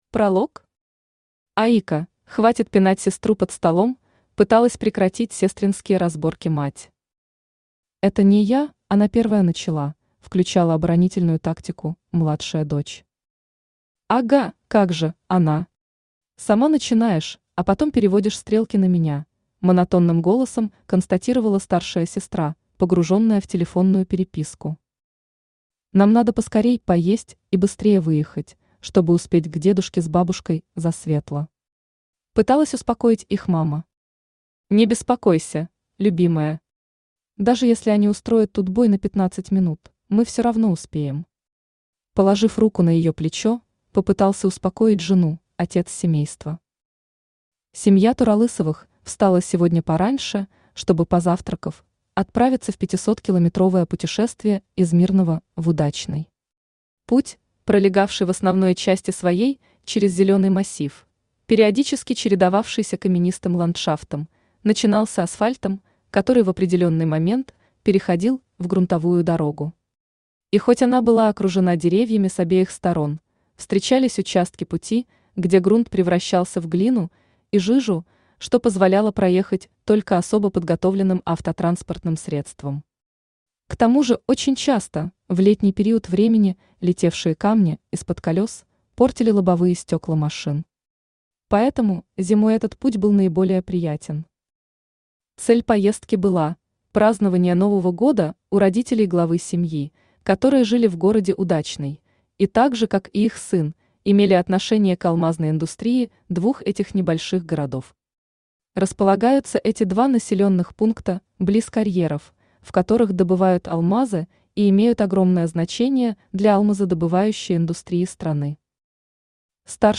Аудиокнига Волчица | Библиотека аудиокниг
Aудиокнига Волчица Автор Александр Швед-Захаров Читает аудиокнигу Авточтец ЛитРес.